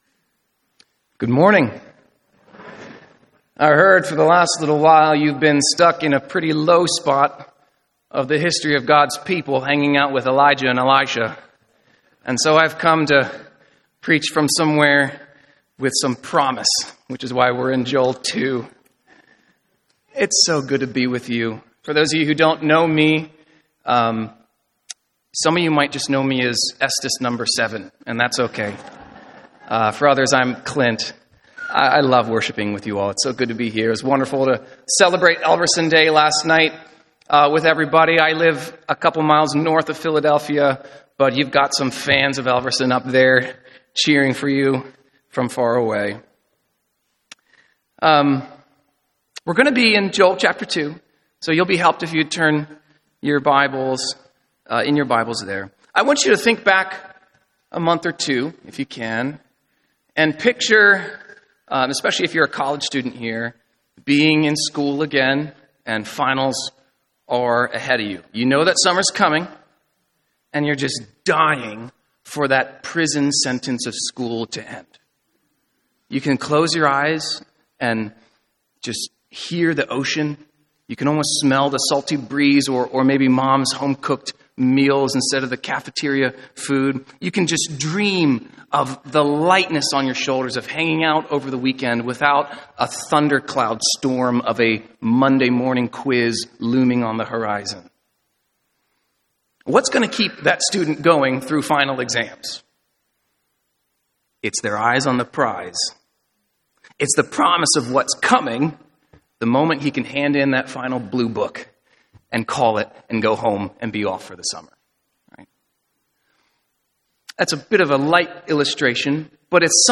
What God Promises for Those Who Repent — Brick Lane Community Church